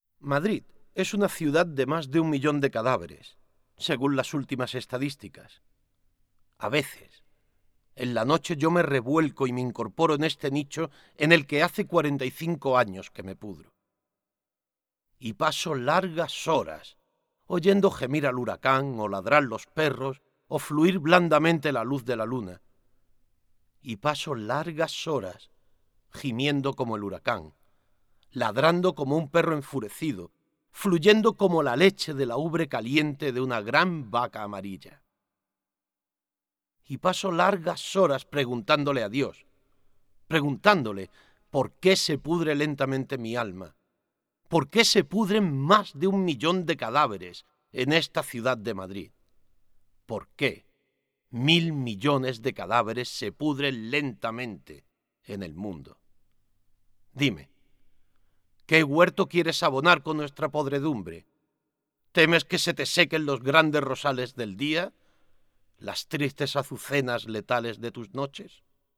poesía
recitar